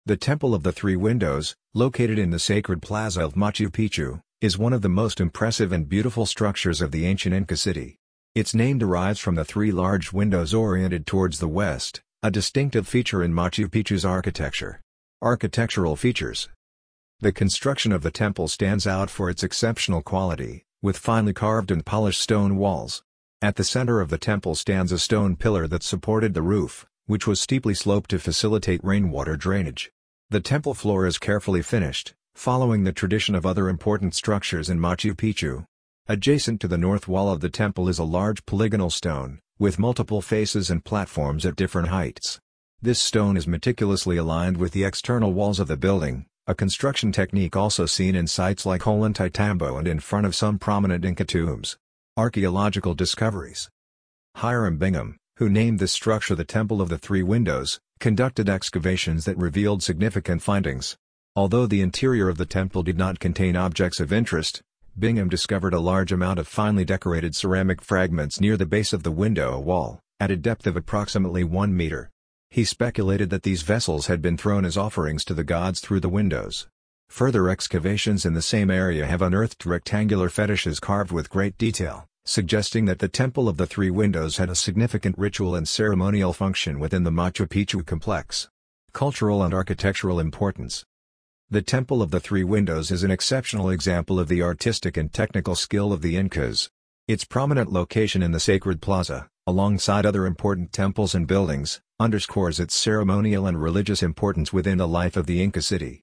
Discover Machu Picchu with Our Immersive FREE Self-Guided Audio Guide